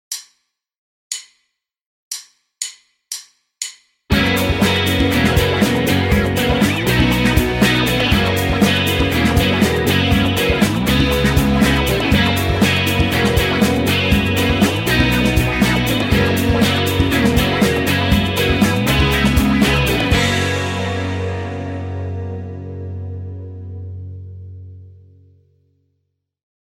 3. Элементы танцевальной музыки
Именно из Мэдчестера в брит-поп пришли открытые струны в аккордах, создающие своеобразный гудящий звук. Часто такие аккорды дополняются использованием педали wah-wah, придающей атмосферности саунду.